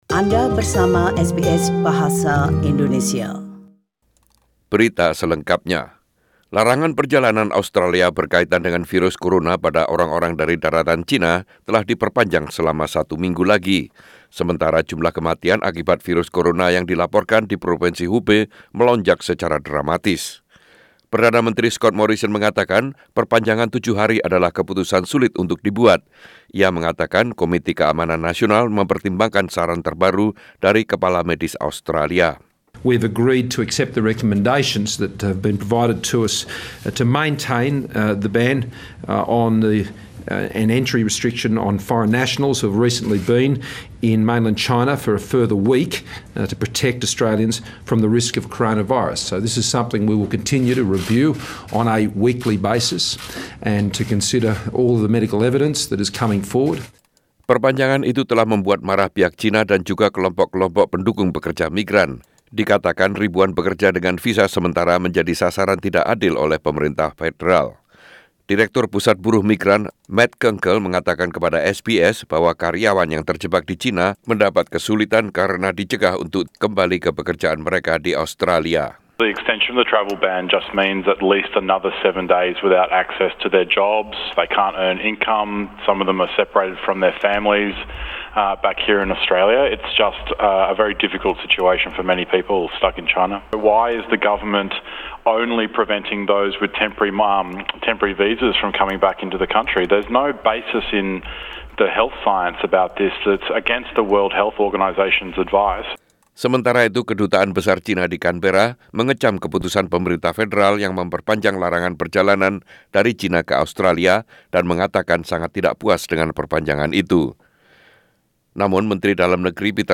SBS Radio news in Indonesian - 14 Feb 2020